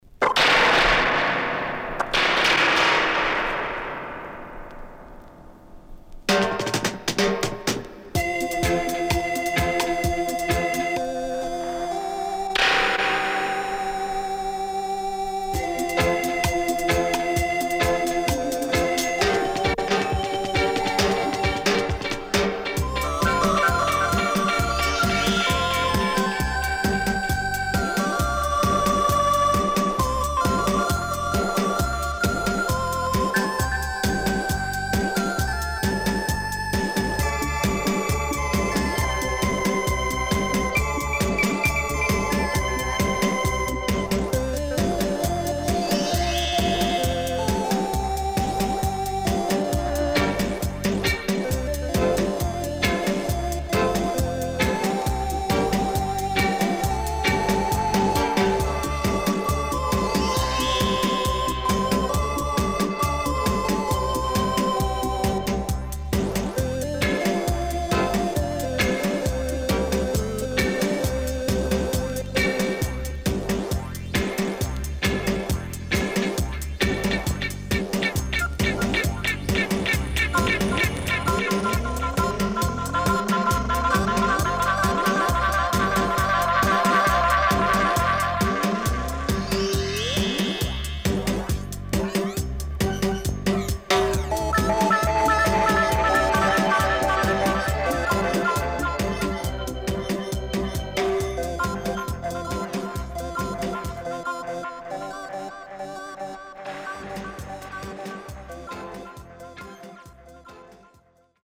HOME > DISCO45 [VINTAGE]  >  RECOMMEND 70's
SIDE A:少しチリノイズ入ります。
プレス起因により数発プチノイズ入ります。